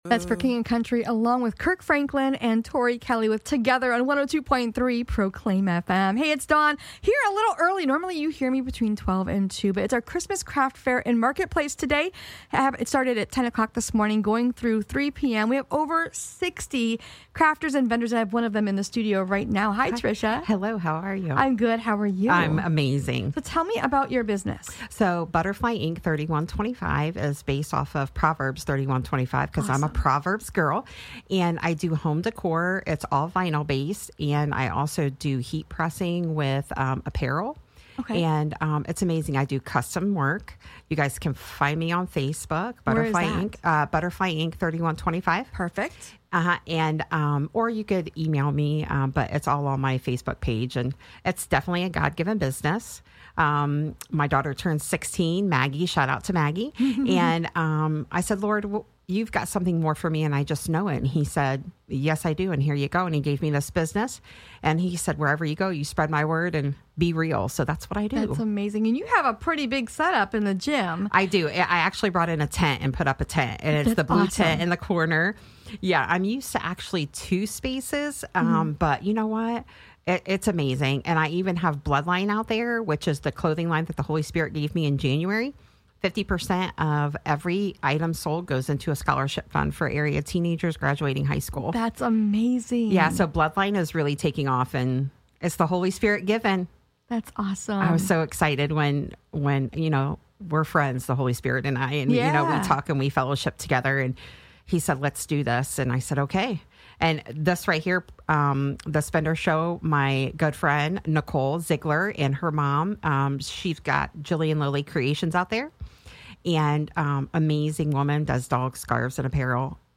Proclaim FM Radio Interview
2023 Craft Show interview with Proclaim FM